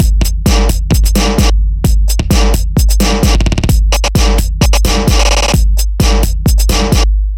描述：宅配音乐
标签： 130 bpm Deep House Loops Drum Loops 1.24 MB wav Key : Unknown
声道立体声